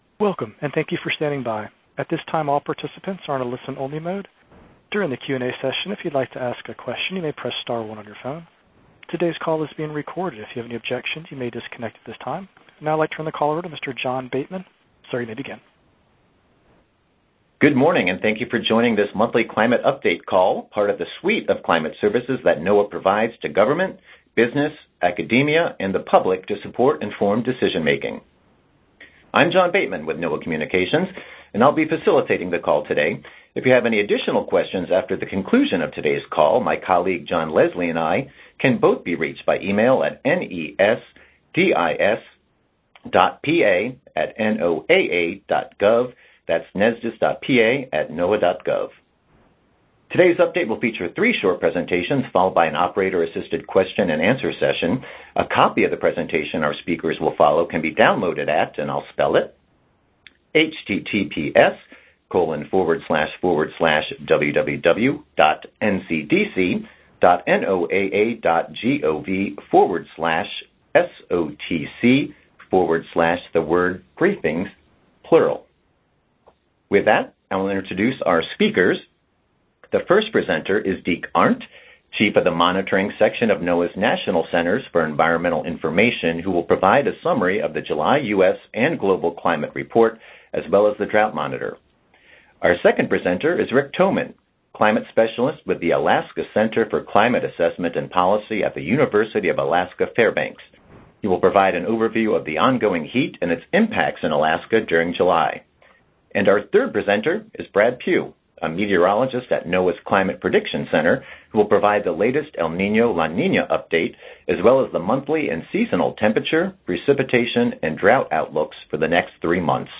AugustClimateTelecon2019.mp3